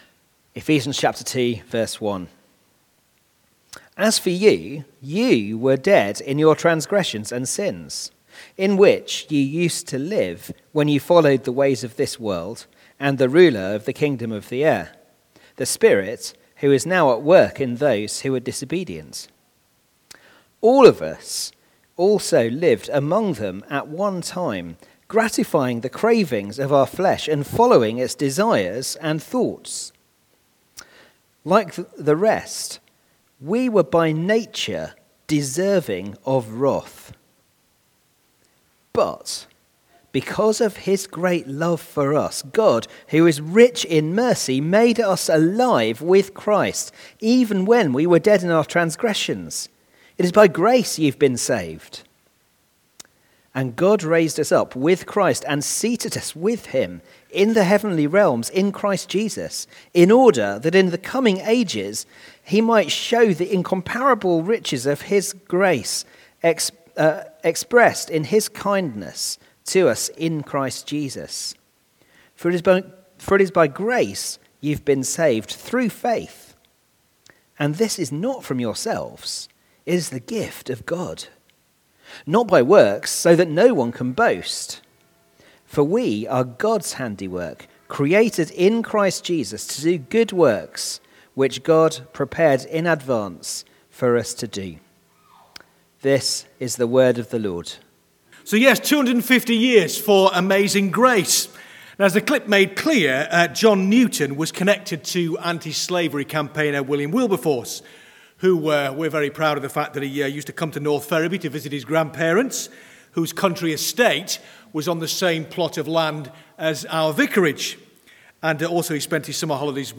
Media Library We record sermons from our Morning Prayer, Holy Communion and Evening services, which are available to stream or download below.
Media for Evening Meeting on Sun 22nd Oct 2023 18:30 Speaker: [unset] Passage: Series: Theme: Sermon Search: